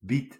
Ääntäminen
Synonyymit kroot beetwortel mangelwortel Ääntäminen Tuntematon aksentti: IPA: /bit/ Haettu sana löytyi näillä lähdekielillä: hollanti Käännös Substantiivit 1. beet Suku: f .